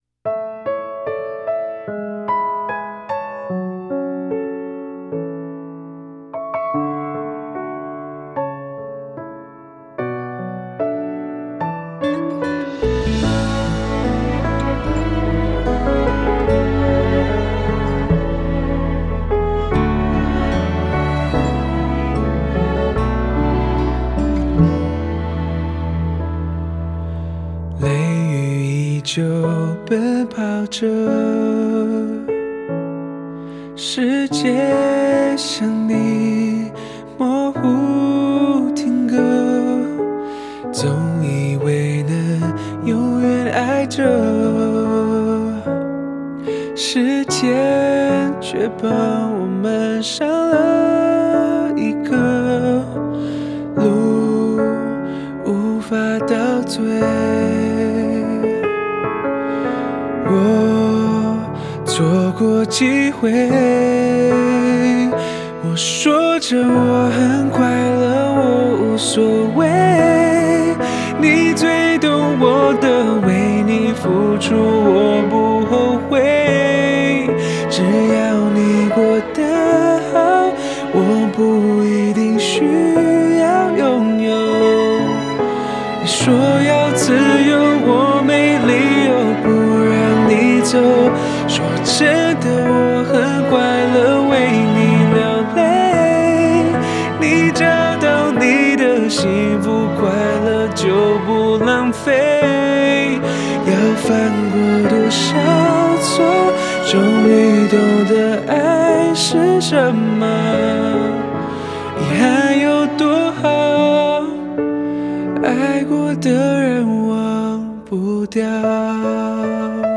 Ps：在线试听为压缩音质节选，体验无损音质请下载完整版 雷雨依旧，奔跑着，世界像你，模糊停格。